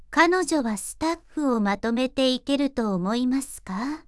voicevox-voice-corpus
voicevox-voice-corpus / ita-corpus /九州そら_ノーマル /EMOTION100_028.wav